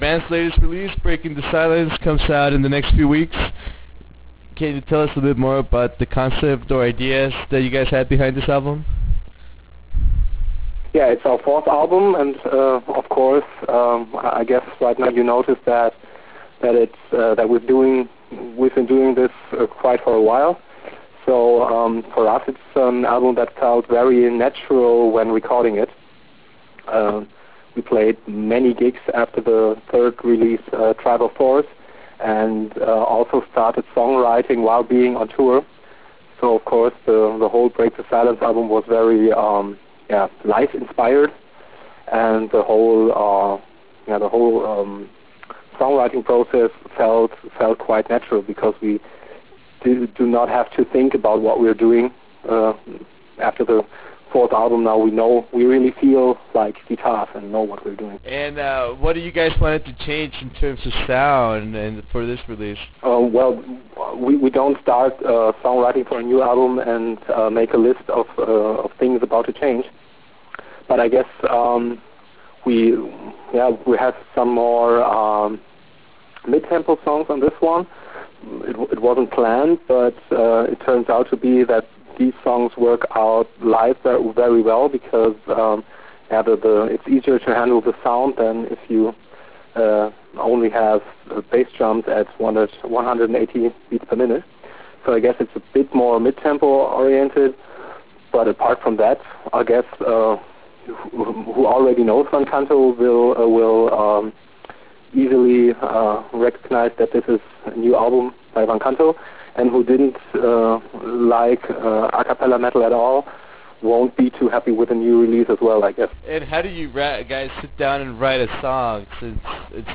In this 20+ minute interview we discuss the new release and the musical progression of the band. We also talk about how their awesome covers are conceived and what to expect from the band in the future.